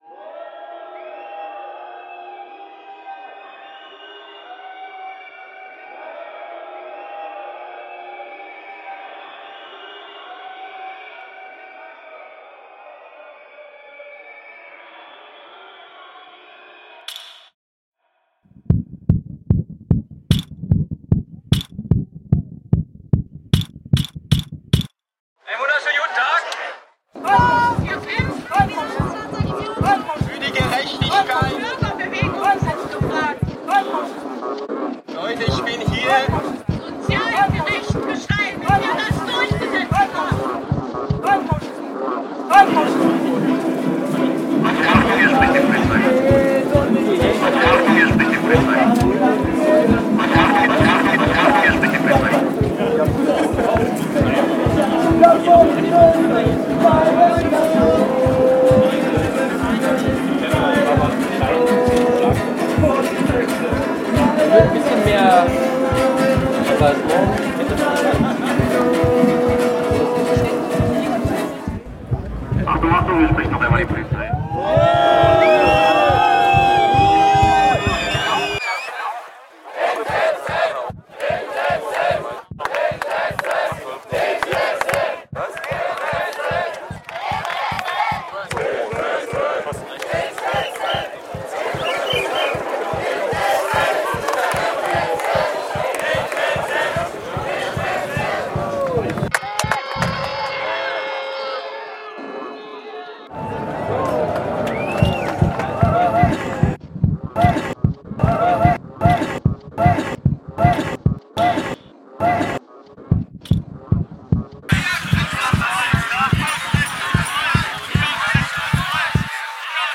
Berlin protest reimagined